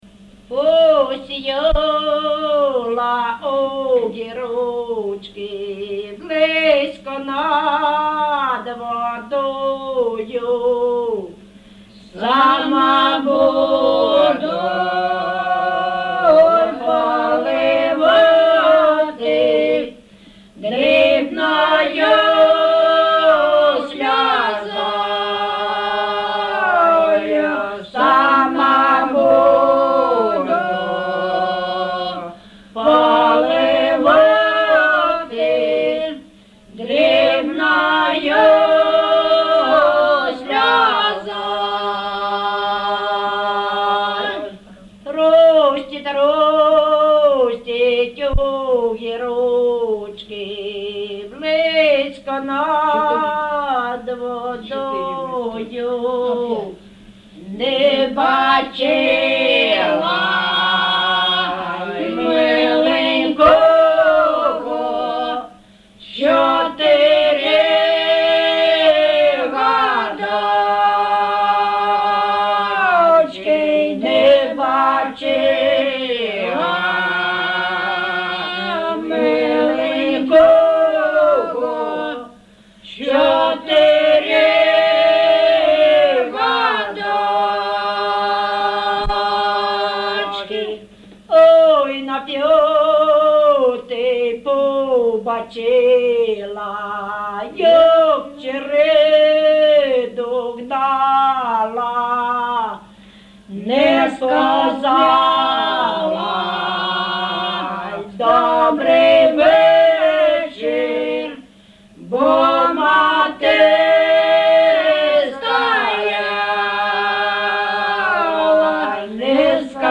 ЖанрПісні з особистого та родинного життя
Місце записум. Старобільськ, Старобільський район, Луганська обл., Україна, Слобожанщина